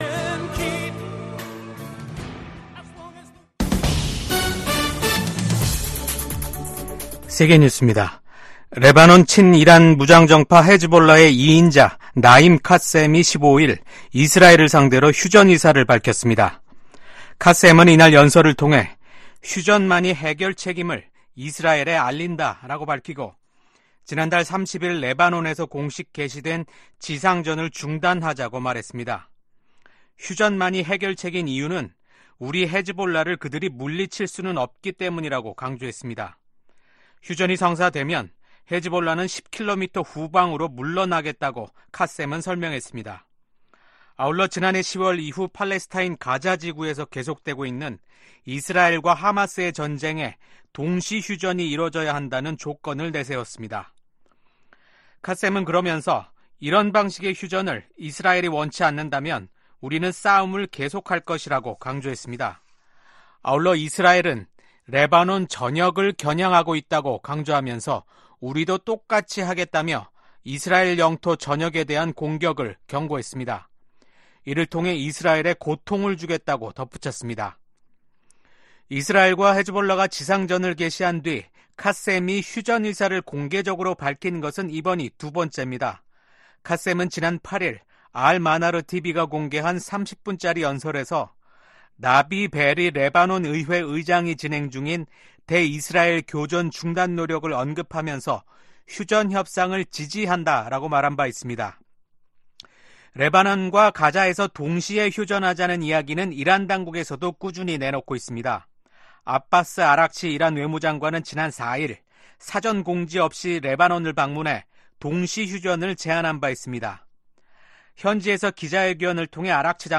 VOA 한국어 아침 뉴스 프로그램 '워싱턴 뉴스 광장' 2024년 10월 16일 방송입니다. 북한의 ‘한국 무인기 평양 침투’ 주장으로 한반도 긴장이 고조되고 있는 가운데 북한군이 오늘(15일) 남북을 잇는 도로들을 폭파했습니다. 16일 서울에서 미한일 외교차관협의회가 열립니다.